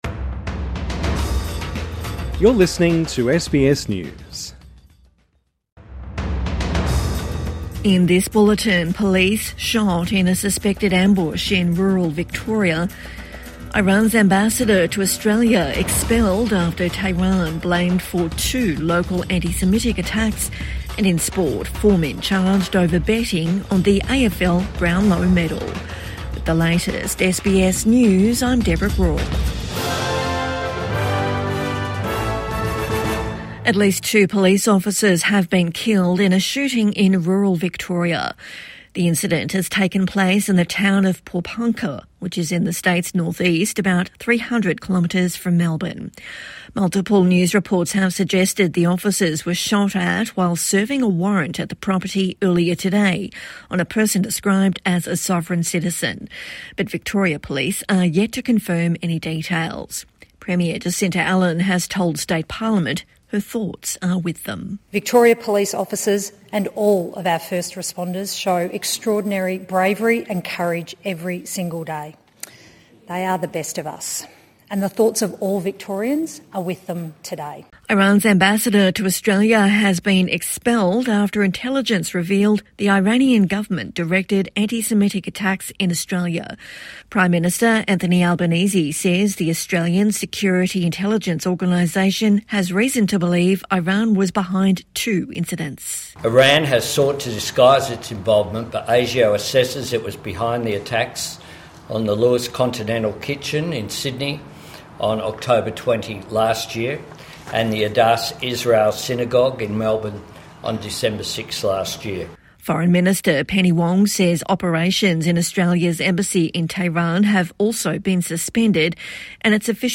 Evening News Bulletin